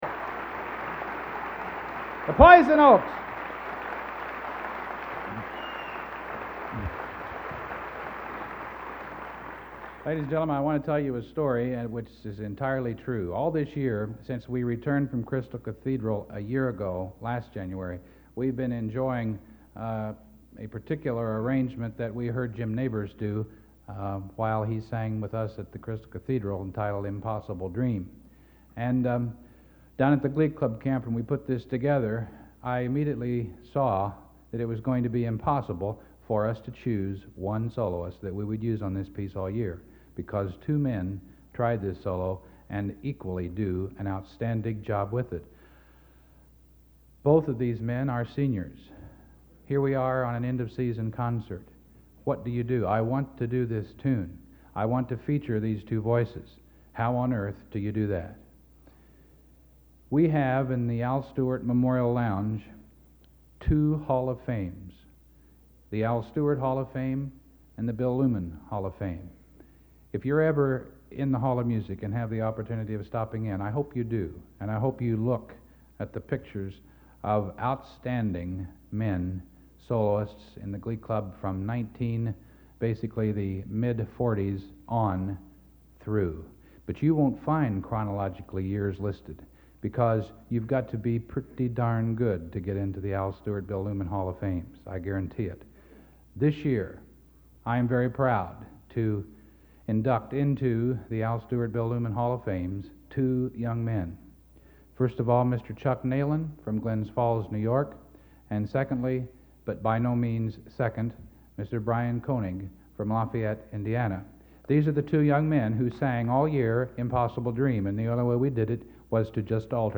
Collection: End of Season, 1986
Location: West Lafayette, Indiana
Genre: | Type: Director intros, emceeing |End of Season